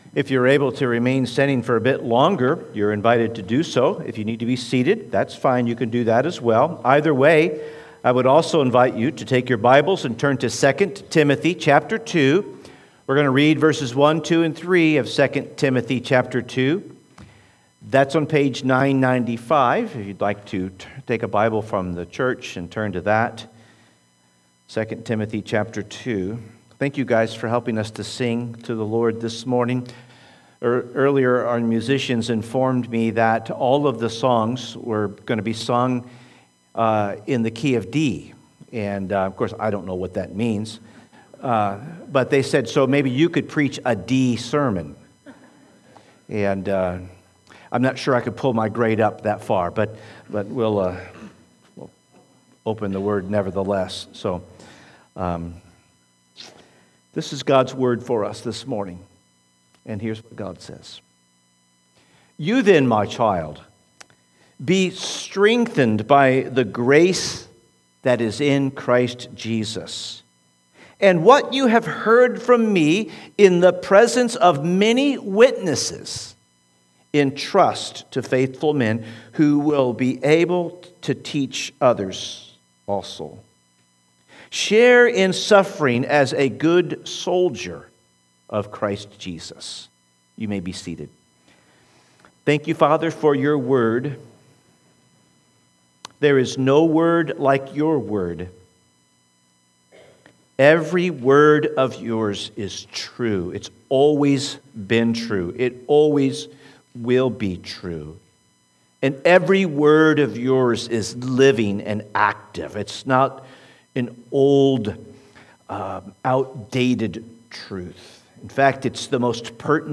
Sermons | First Baptist Church St Peters